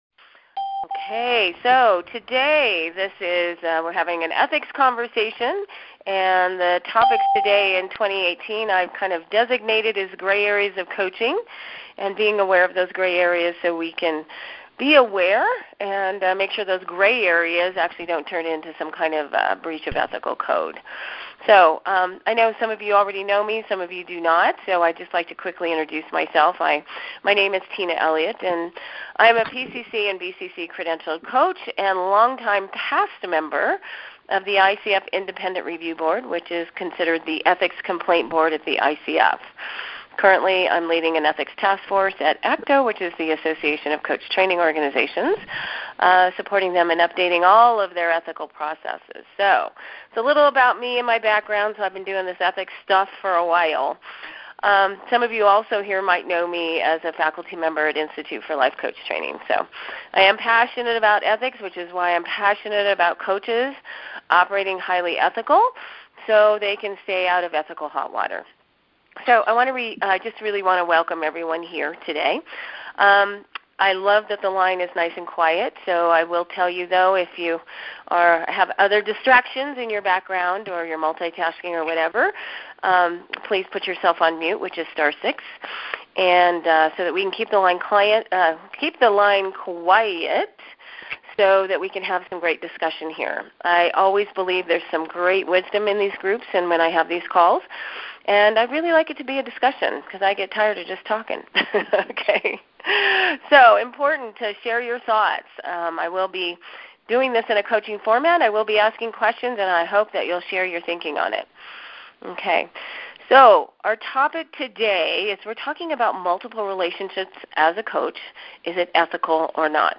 In this lively discussion, we’ll be diving into multiple relationships with clients and sponsors and how without full awareness it could land a coach in ethical hot water.